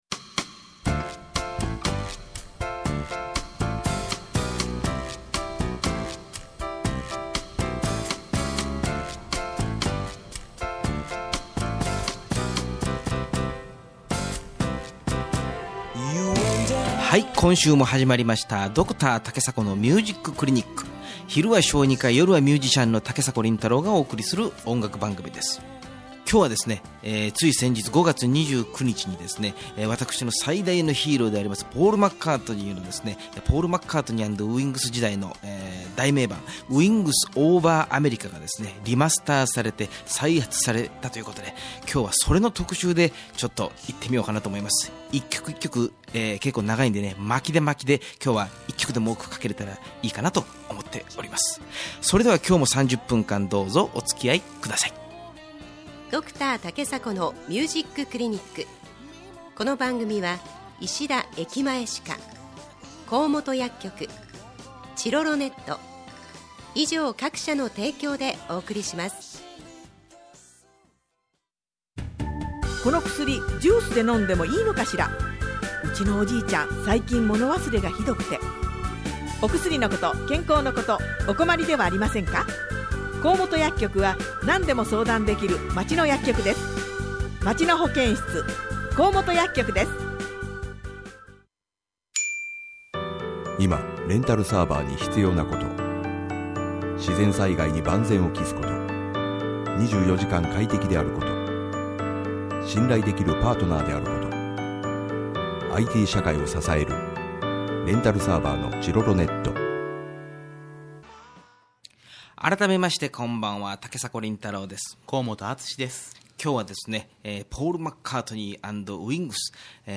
第26回放送録音をアップしました。